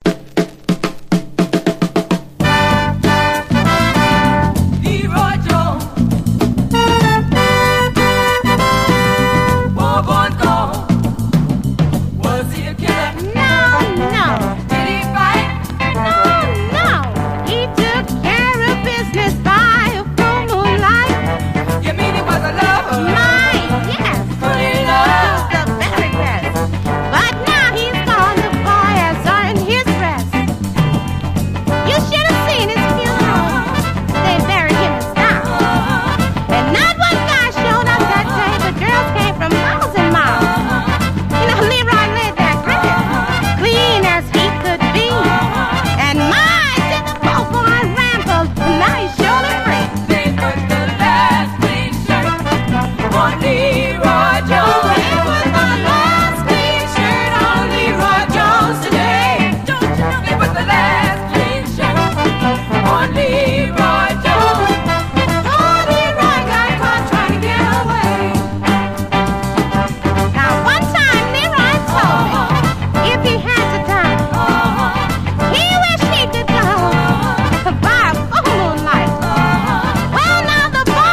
ポップさ全開！